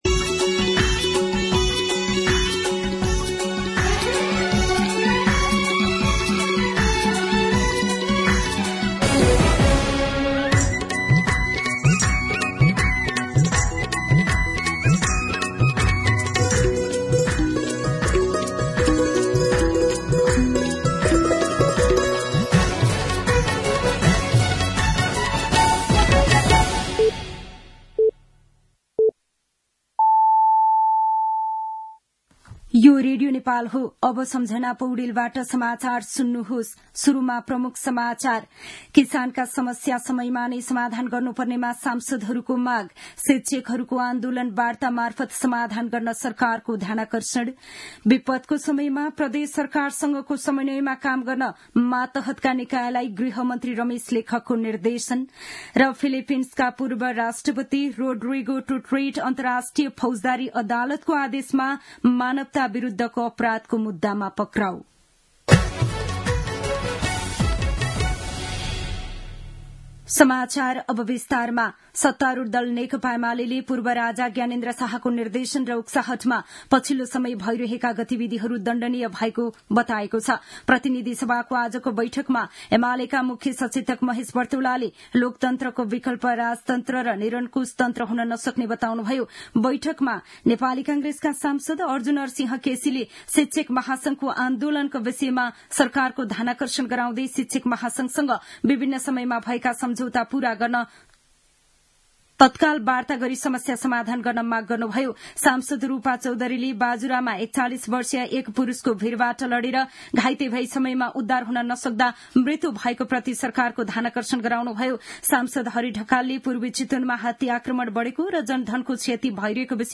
दिउँसो ३ बजेको नेपाली समाचार : २८ फागुन , २०८१